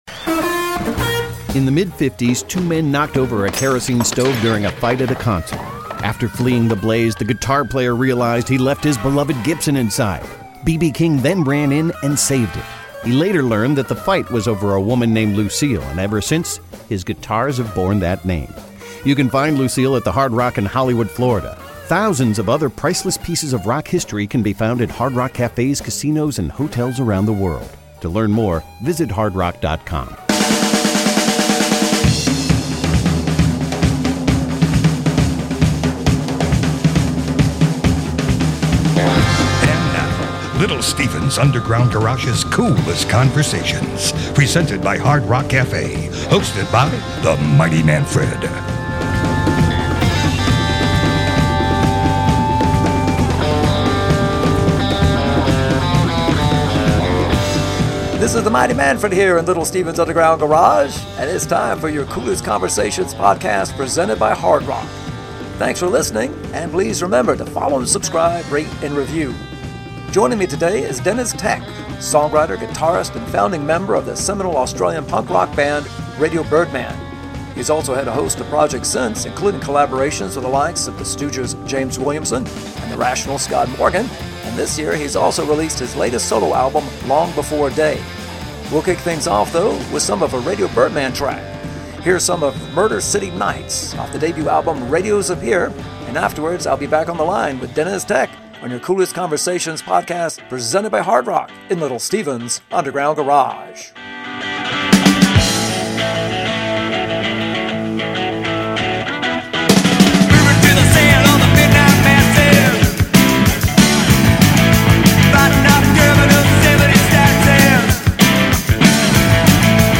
guest this week is Deniz Tek of Radio Birdman!